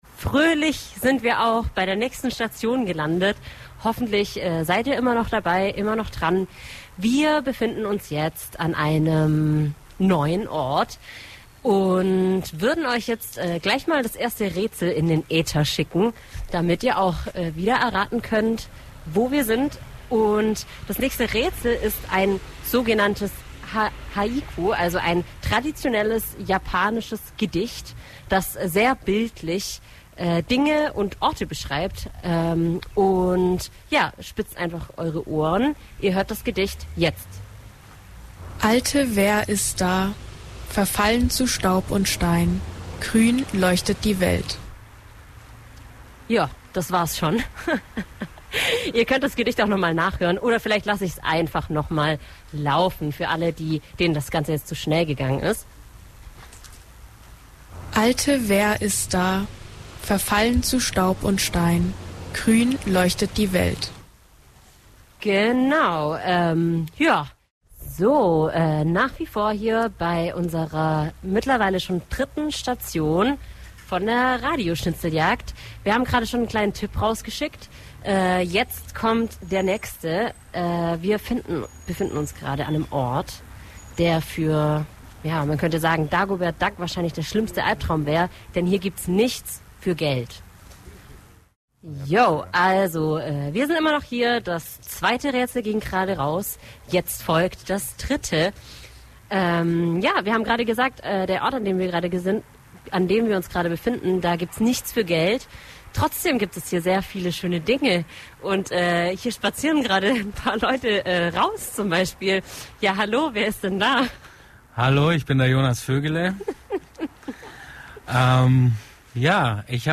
Im Rahmen einer spaßigen und wilden Radio-Schnitzeljagd waren wir mit unserem Radio auf Rädern den ganzen Tag in Ulm und Neu-Ulm unterwegs. Wunderschöne Radiomomente live und direkt von der Straße, tolle Begegnungen, kalte Nasen, überraschende Sonnenstrahlen und herausfordernde Verkehrssituationen.
Weiter geht´s mit Station Nr. 3: Die Tauschbörse Neu Ulm.